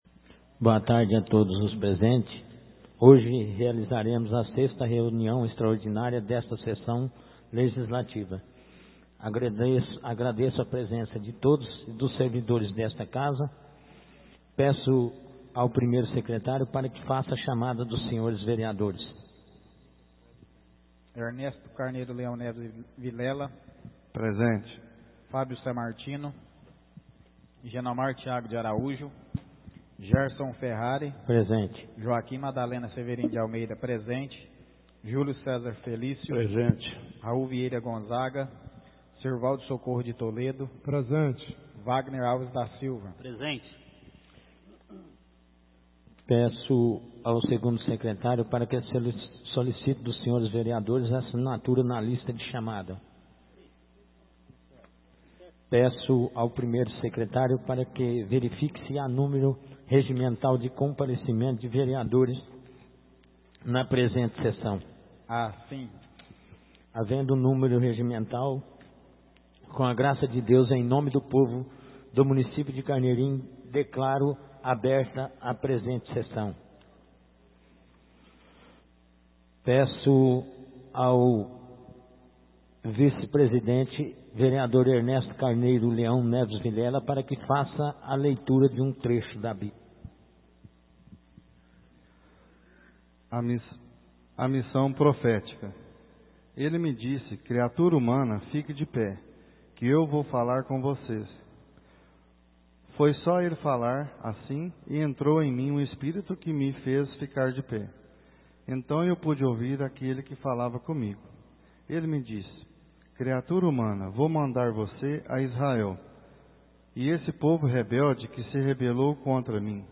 Áudio da 05ª reunião extraordinária de 2017, realizada no dia 19 de Janeiro de 2017, na sala de sessões da Câmara Municipal de Carneirinho, Estado de Minas Gerais.